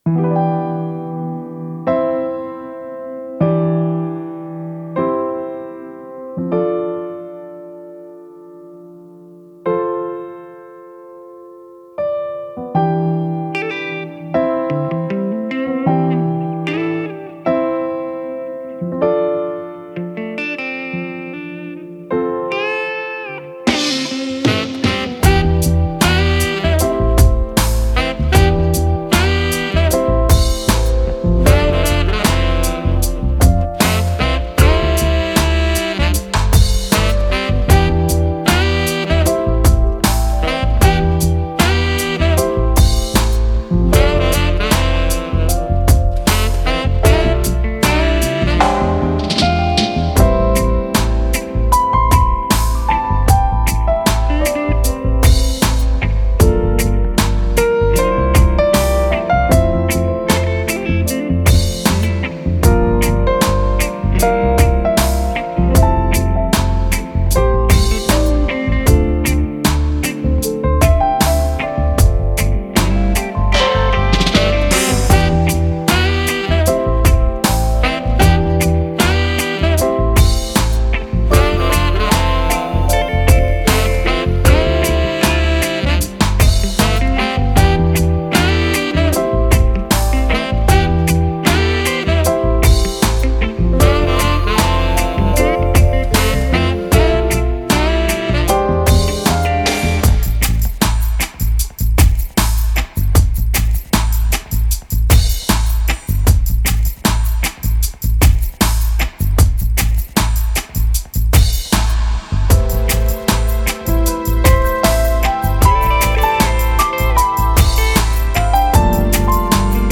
ジャンル(スタイル) REGGAE / LOVERS ROCK